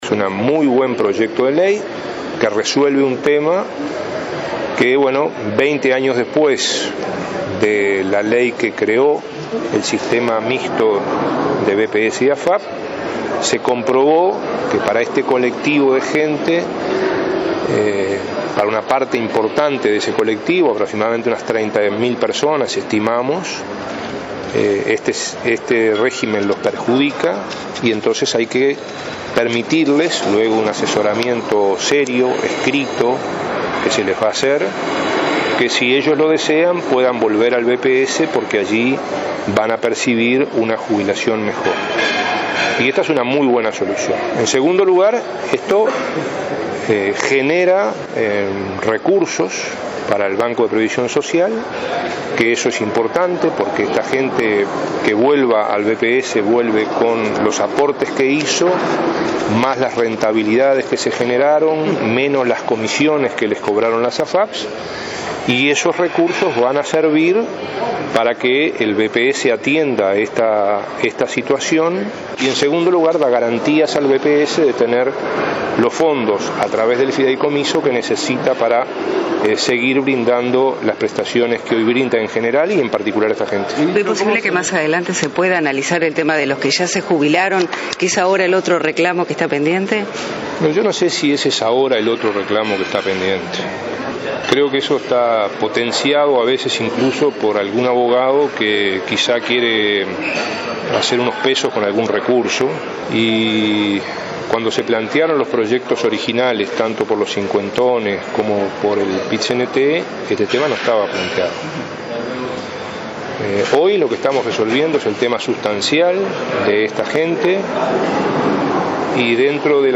“El proyecto de ley enviado al Parlamento resuelve el problema generado hace 20 años con los autollamados ‘cincuentones’”, recordó el ministro de Trabajo, Ernesto Murro. Dijo que es un “muy buen proyecto” que contempla los presentados por este colectivo y el PIT- CNT.